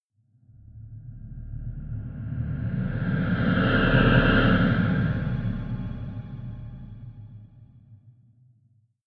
Gemafreie Sounds: Sweeps und Swells